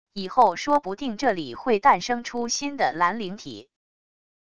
以后说不定这里会诞生出新的蓝灵体wav音频